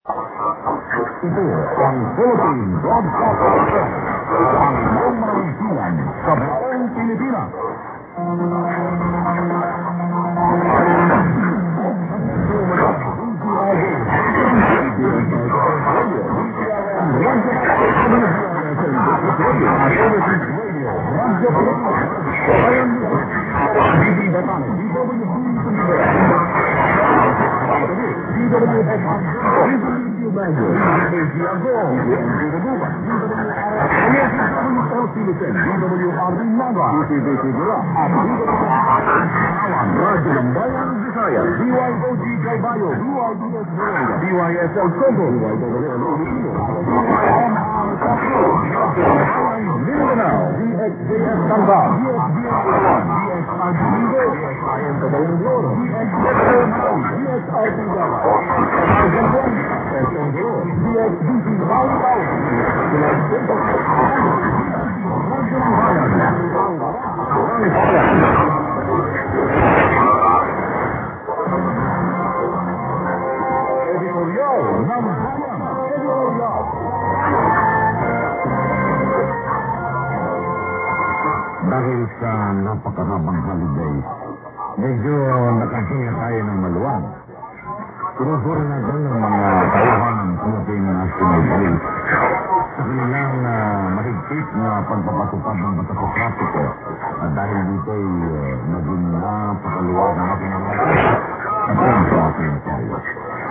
中継局のコールサインと所在地をアナウンスしています。今日は、ちょっと下の昨日に比べると落ちるかもしれませんが、隣の9580kHzが音楽を流すとサイドを受けてしまいます。